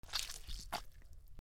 グロテスク、汁物